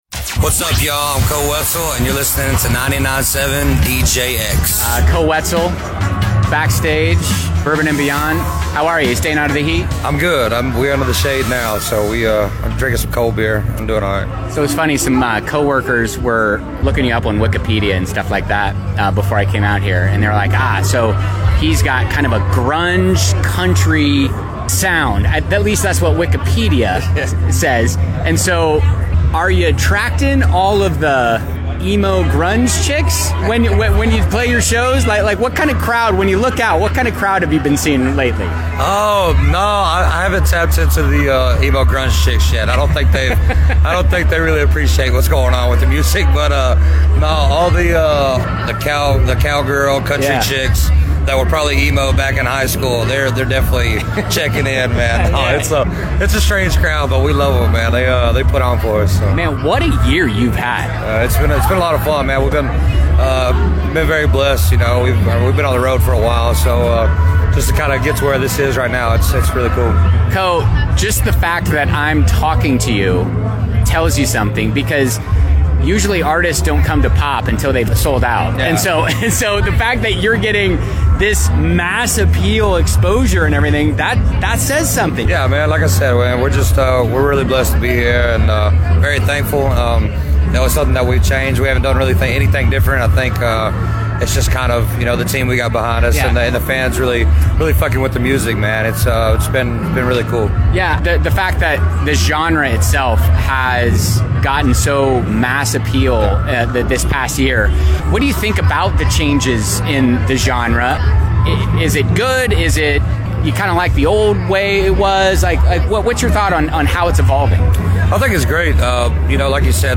Koe Wetzel Bourbon & Beyond Interview